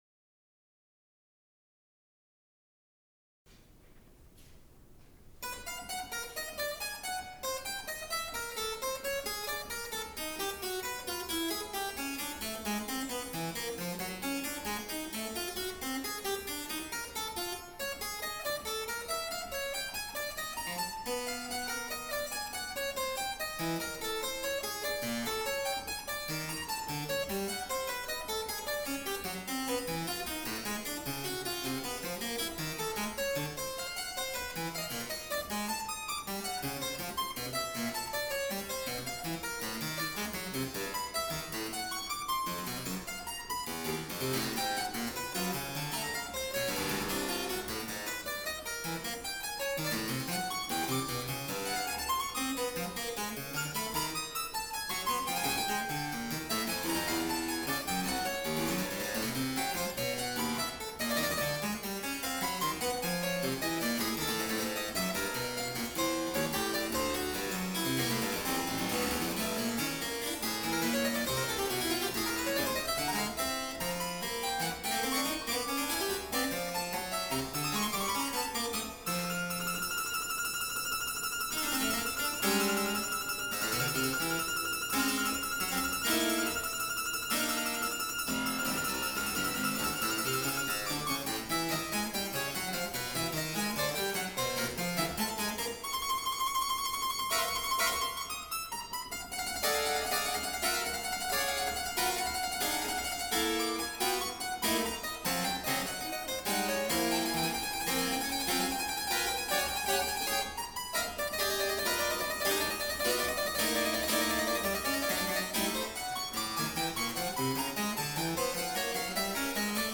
Harpsichord
SuiteHarpsichord.mp3